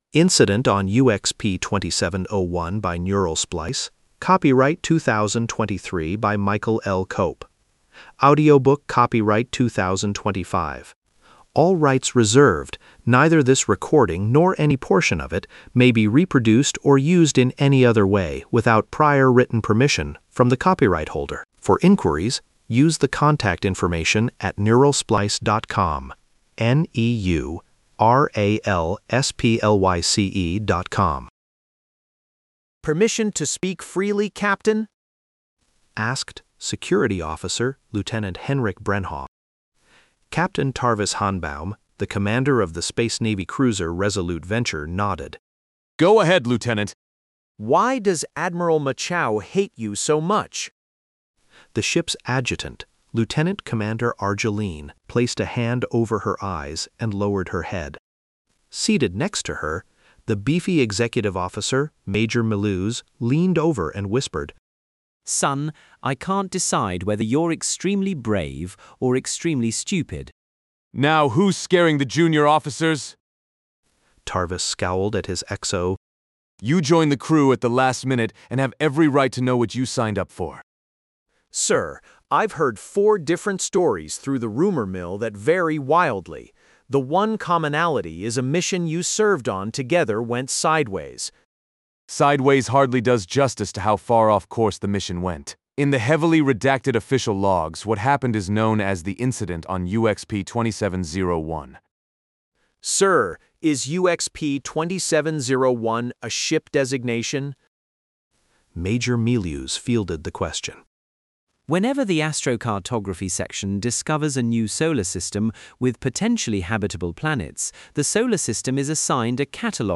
This short story provides the backstory for their conflicts in the novel. Return to Bookshelf Incident on UXP-27-01 Donate $1 Download ebook Listen to Audiobook Download audiobook